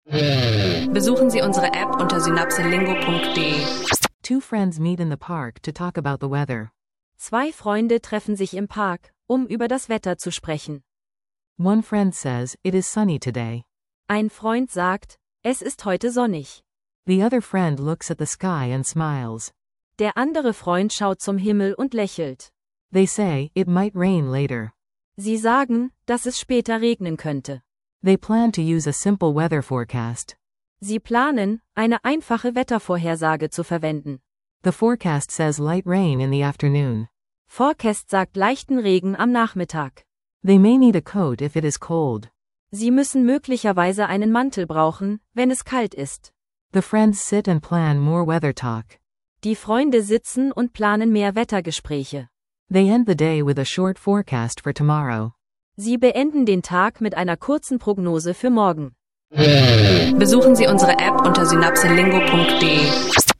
In dieser Folge üben zwei Freunde einfache Wetterphrasen und eine